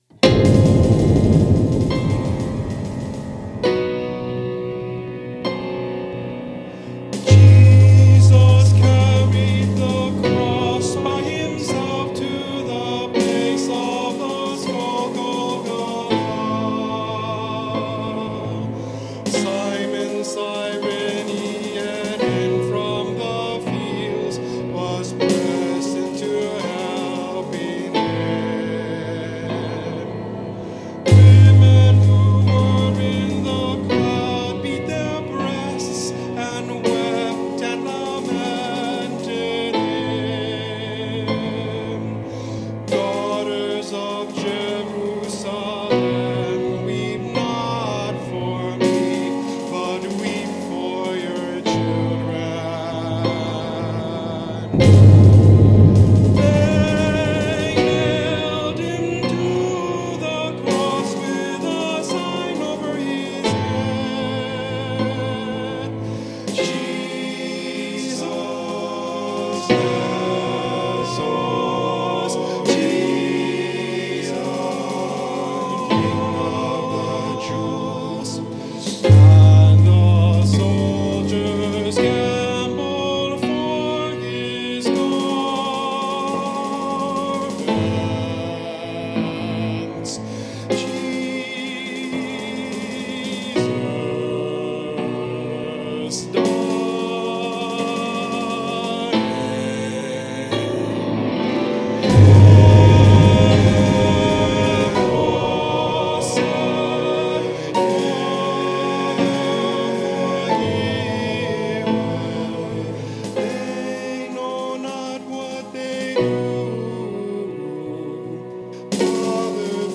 1984   following mp3's are "practice"